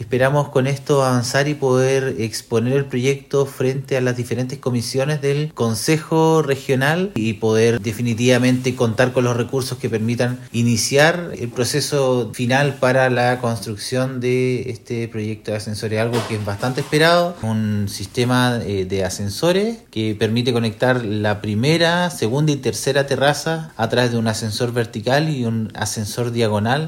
El seremi de Transporte, Pablo Joost, dijo que ahora deben presentar la iniciativa al CORE para conseguir recursos.